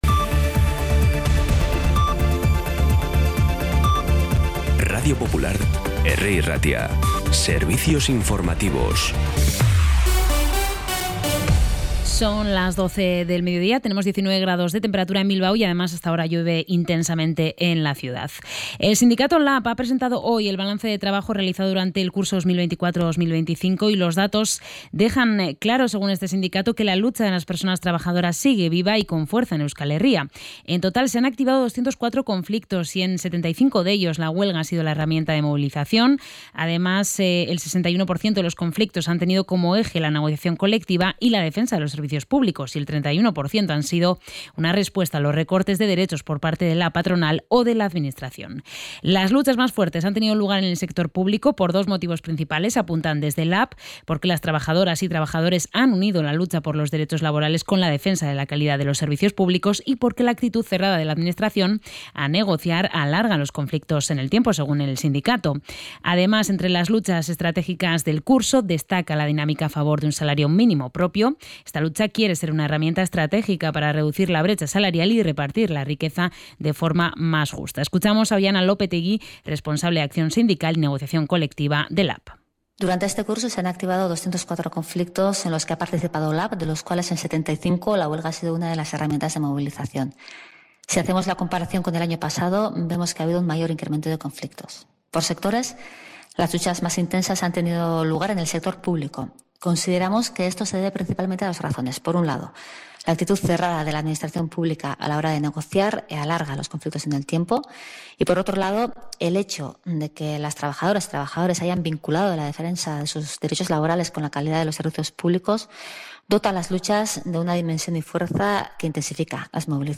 Boletín informativo de las 12 h